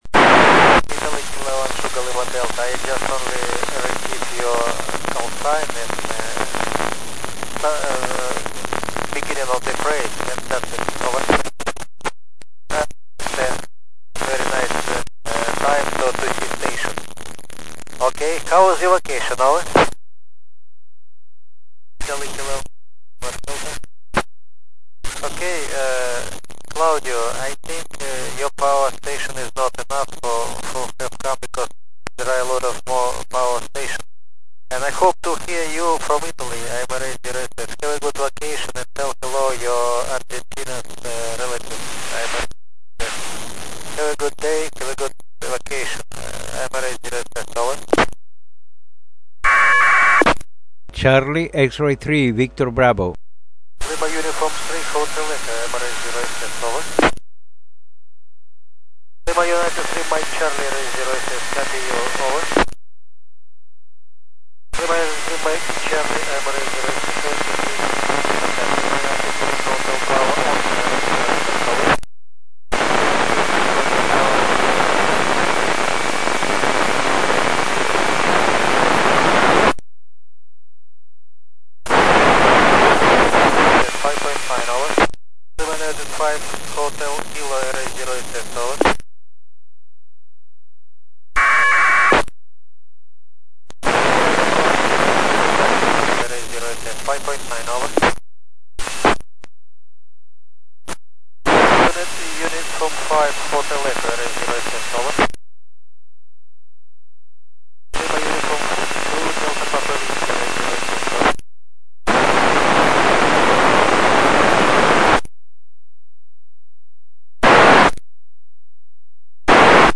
Recored Radio Contacts with the ISS
11 years ago, my contact with Valery Korzun ISS Commander